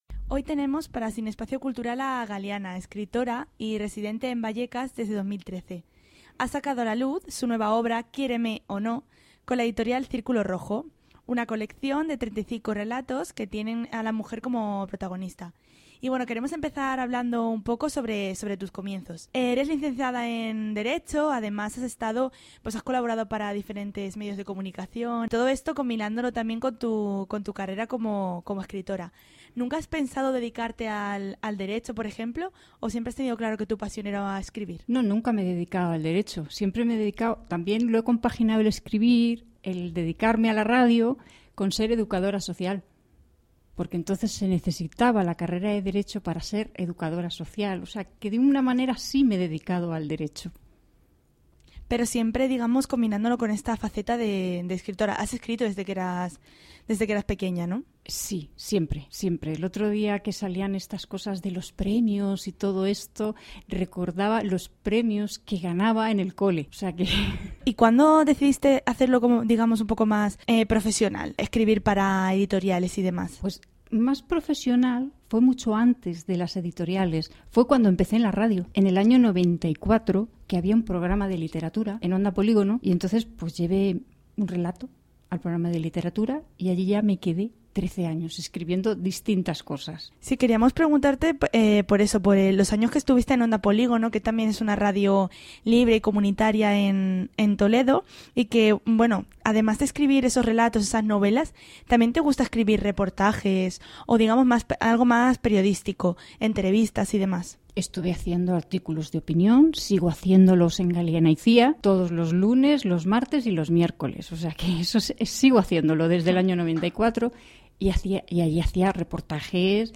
os dejamos la entrevista que nos hizo en este vídeo.